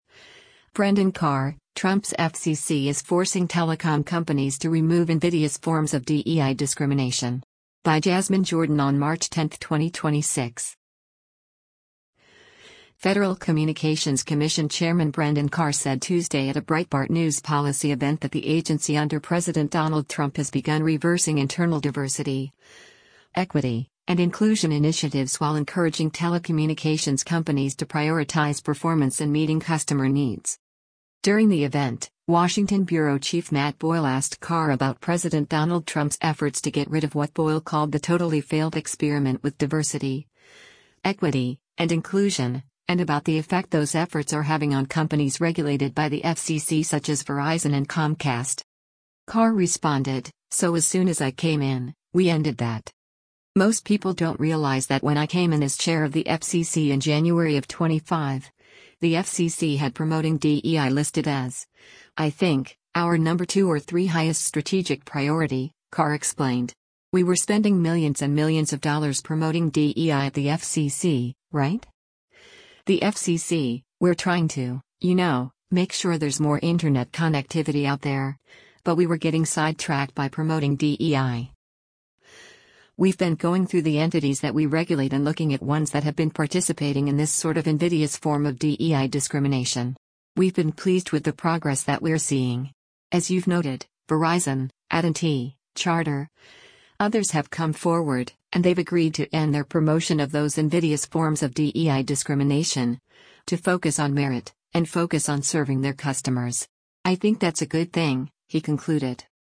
Federal Communications Commission Chairman Brendan Carr said Tuesday at a Breitbart News policy event that the agency under President Donald Trump has begun reversing internal diversity, equity, and inclusion initiatives while encouraging telecommunications companies to prioritize performance and meeting customer needs.